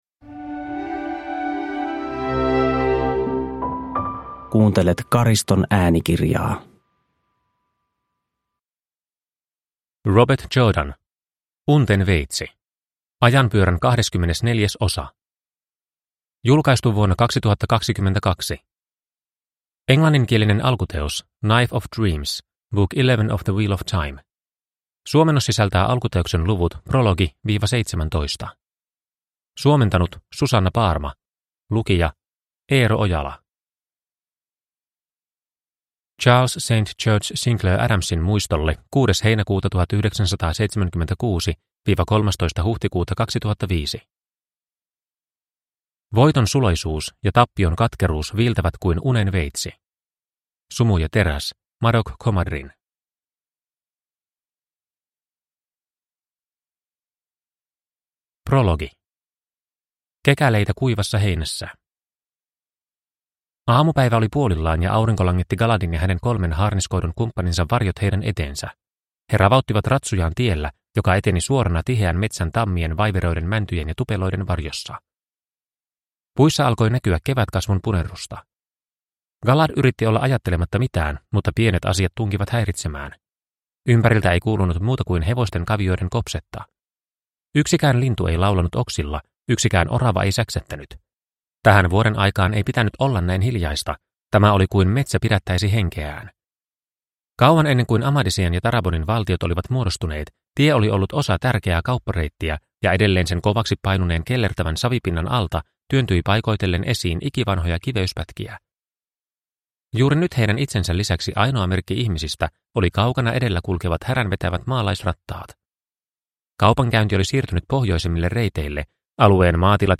Unten veitsi – Ljudbok – Laddas ner